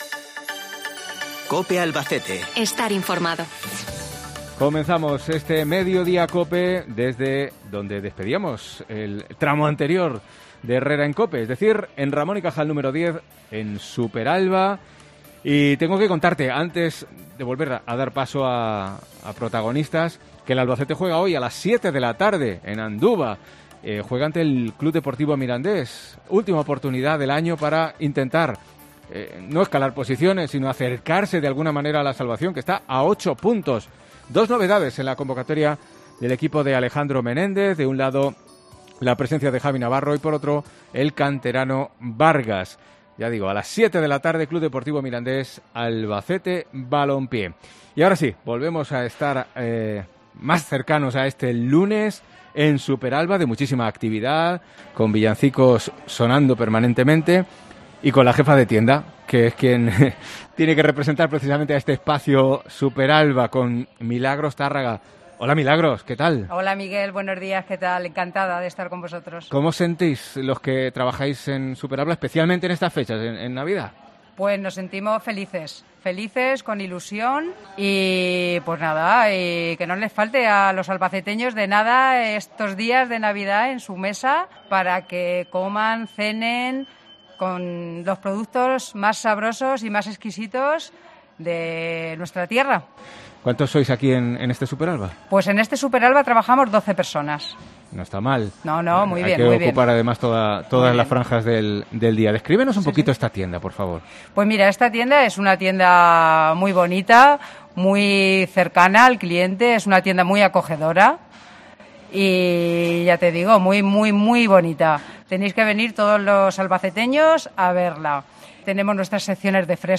Desde el supermercado de Ramón y Cajal de esta empresa con más de 100 años de trayectoria en Albacete